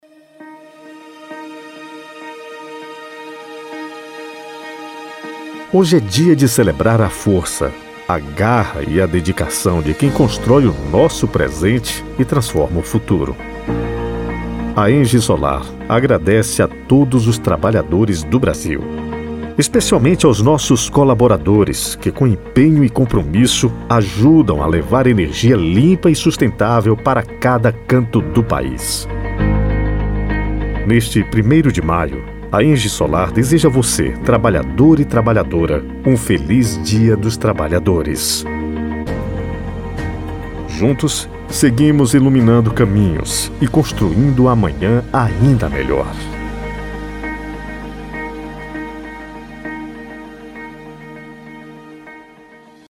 Spot Comercial